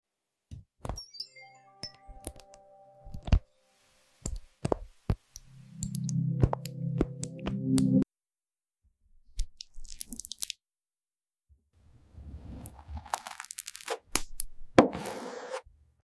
ASMR: Soap Symphony 🎶🫧 Sound Effects Free Download